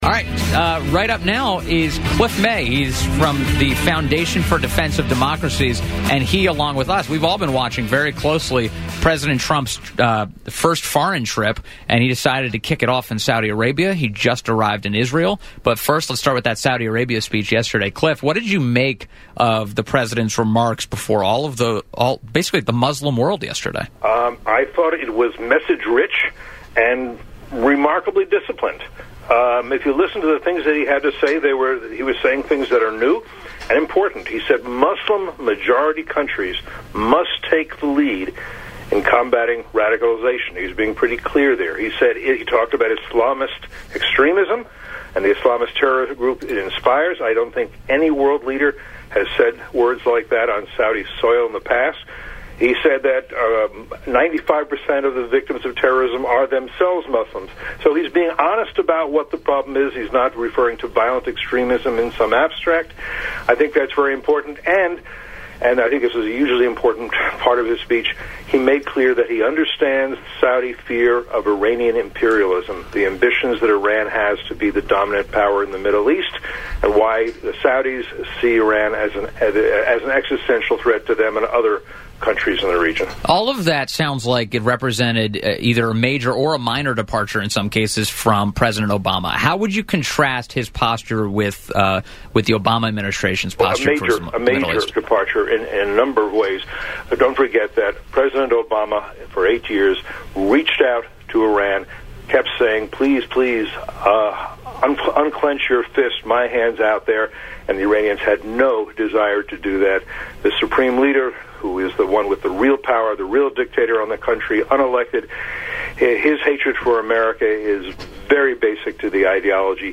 WMAL interview; Cliff May, 05.22.17